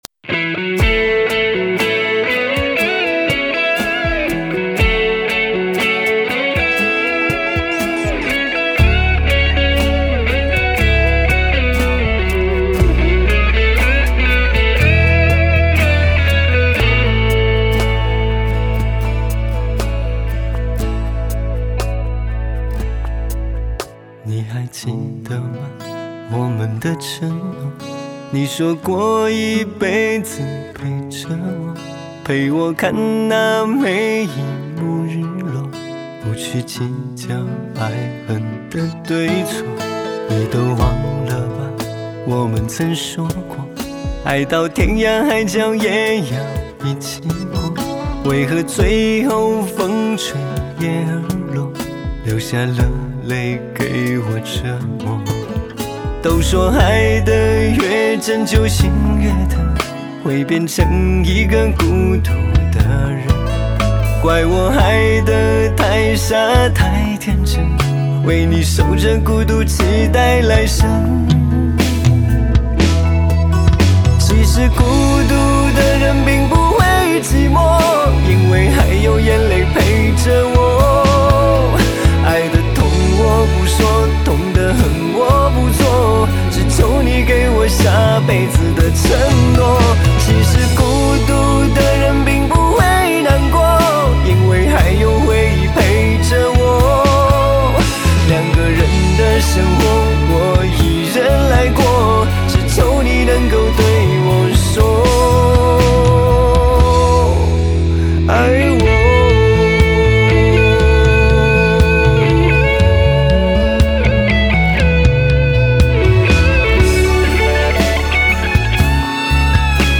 流行 收藏 下载